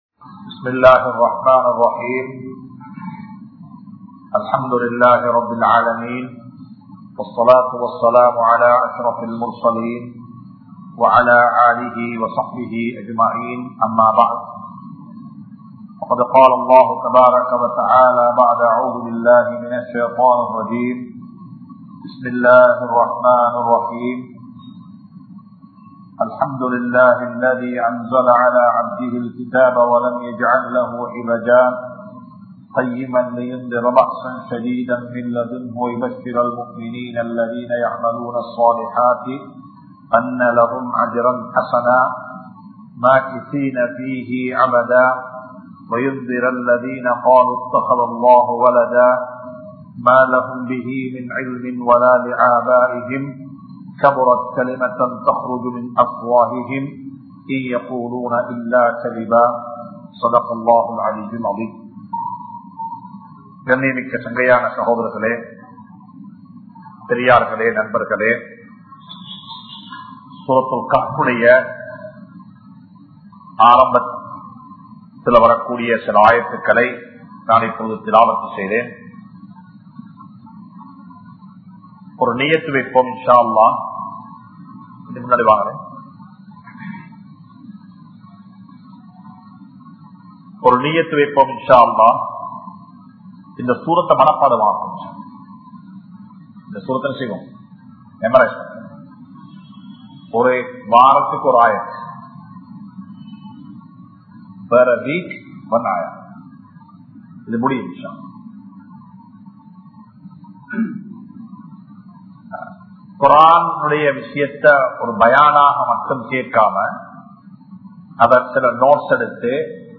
Soorathul Kahf Thafseer Part-02 (சூரத்துல் கஹ்ப் தப்ஸீர் பாகம்-02) | Audio Bayans | All Ceylon Muslim Youth Community | Addalaichenai
Colombo 04, Majma Ul Khairah Jumua Masjith (Nimal Road)